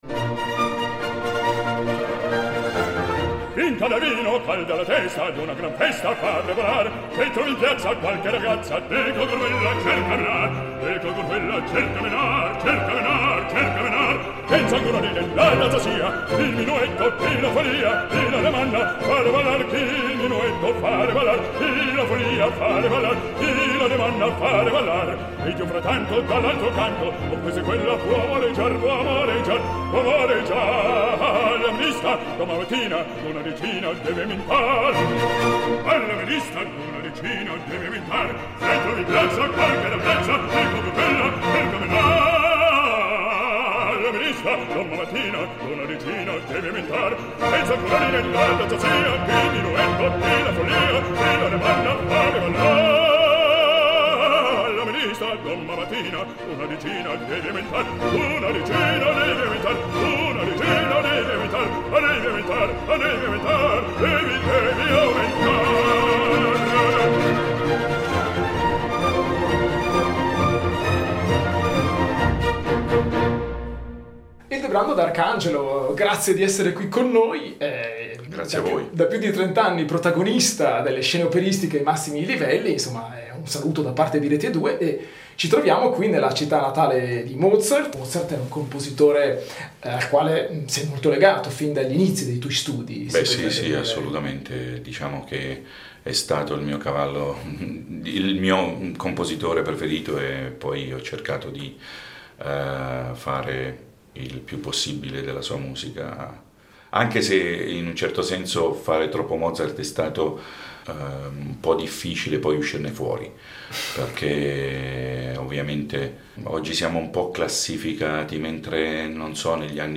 Incontro con Ildebrando d’Arcangelo